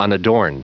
Prononciation du mot unadorned en anglais (fichier audio)
Prononciation du mot : unadorned